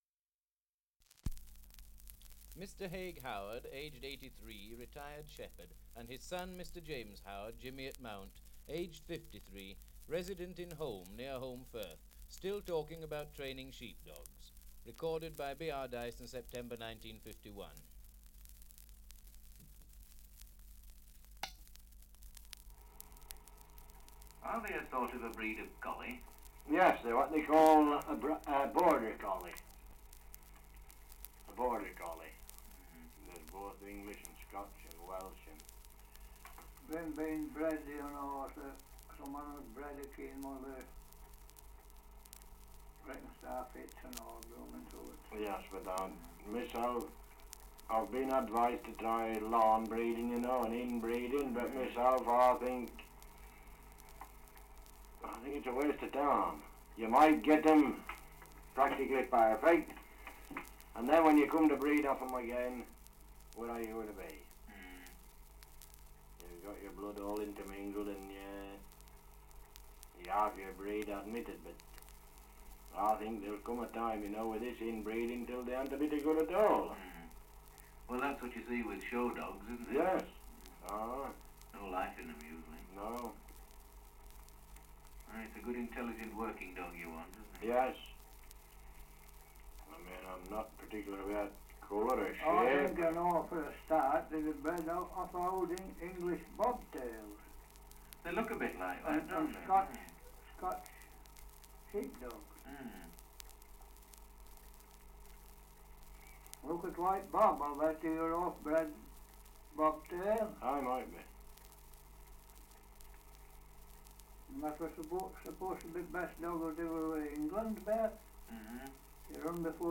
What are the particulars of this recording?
Title: Survey of English Dialects recording in Holmbridge, Yorkshire 78 r.p.m., cellulose nitrate on aluminium